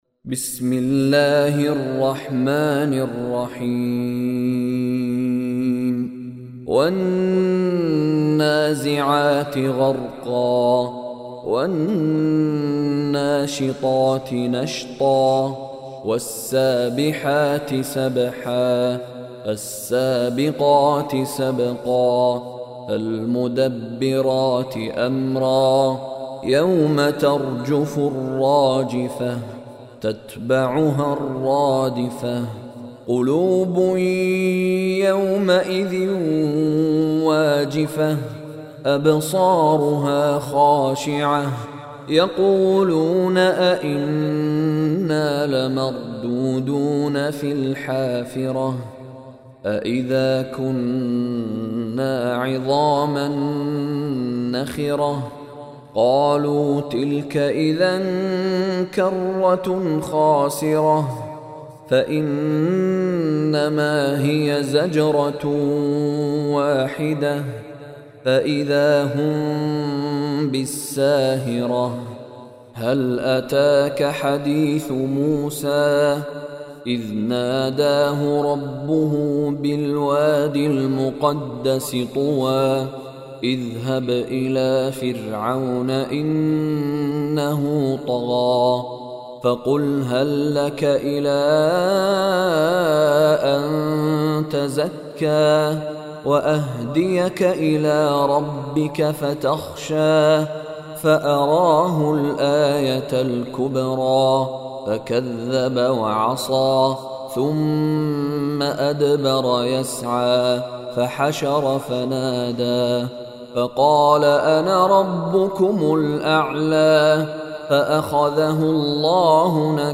Surah An-Naziat Recitation by Mishary Rashid
Surah An-Naziat is 79 Surah of Holy Quran. Listen online mp3 recitation of Surah An-Naziat, recited in Arabic, in the voice of Sheikh Mishary Rashid Alafasy.